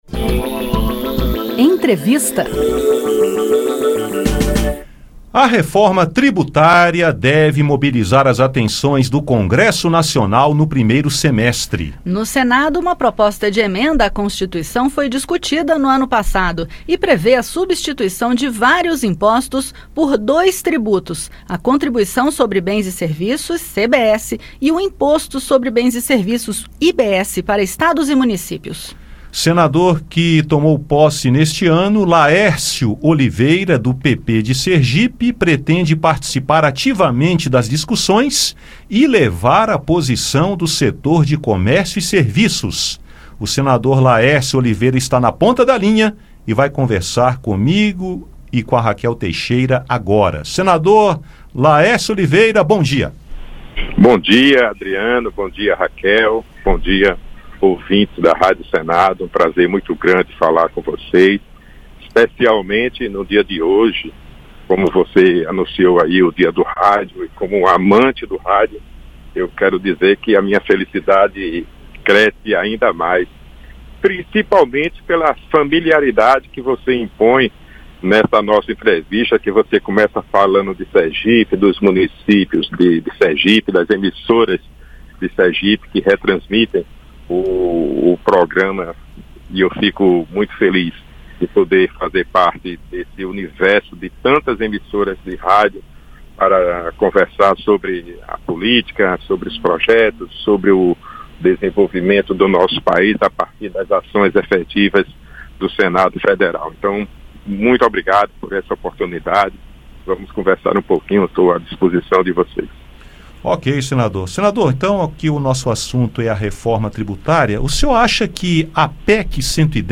O senador Laércio Oliveira (PP-SE) conversou com o Conexão Senado sobre Reforma Tributária, tema que deve mobilizar o Congresso Nacional no primeiro semestre.